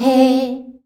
HEY     C.wav